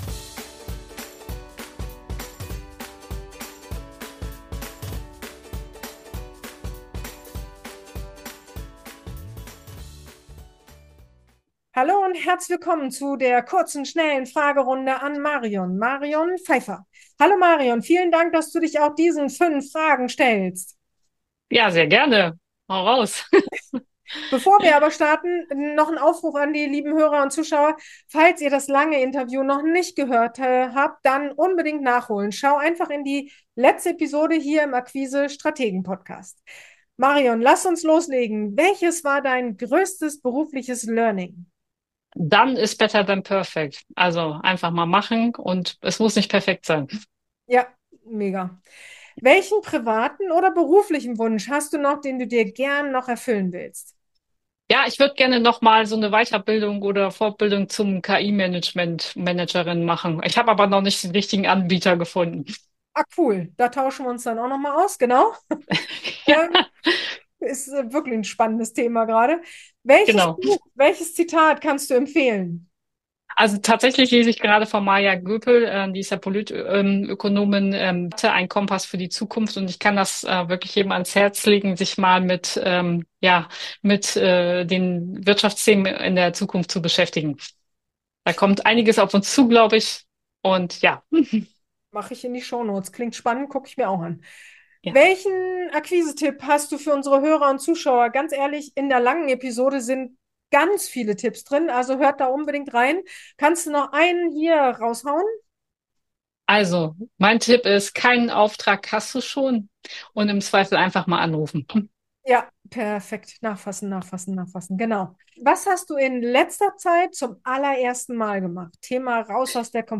In dieser Episode erwartet Dich eine schnelle Fragerunde mit